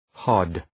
{hɒd}